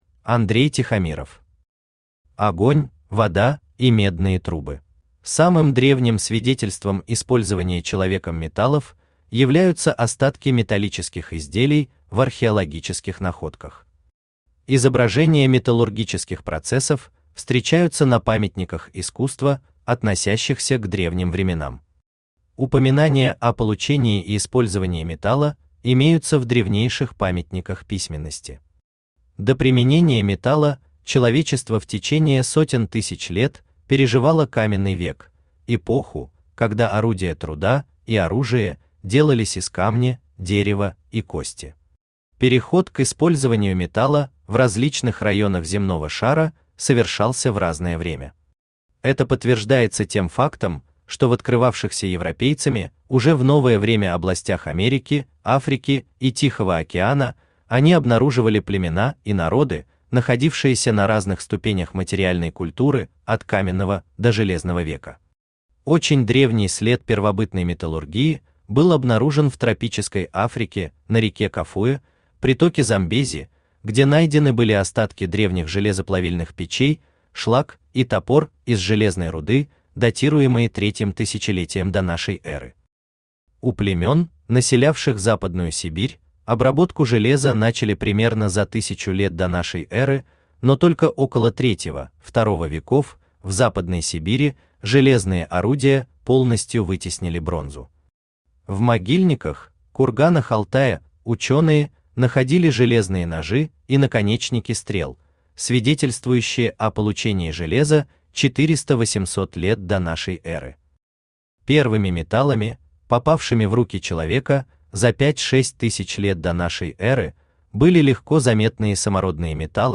Aудиокнига Огонь, вода и медные трубы Автор Андрей Тихомиров Читает аудиокнигу Авточтец ЛитРес.